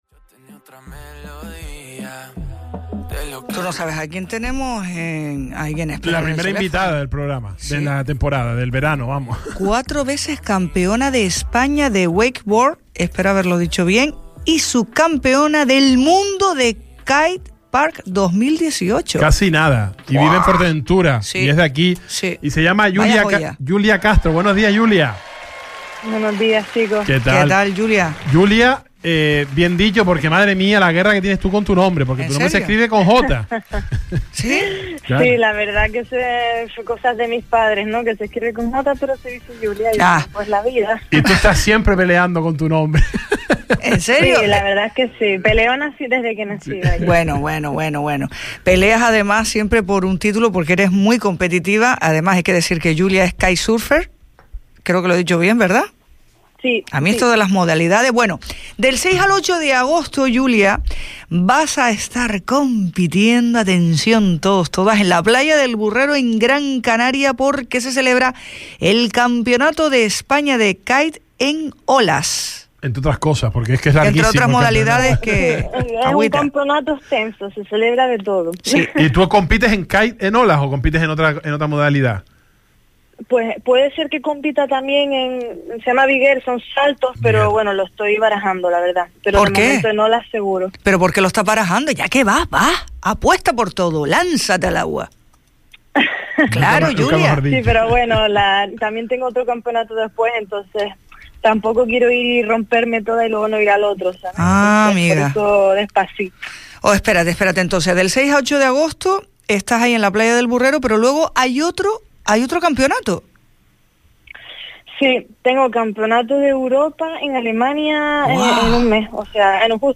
El Salpicón, entrevista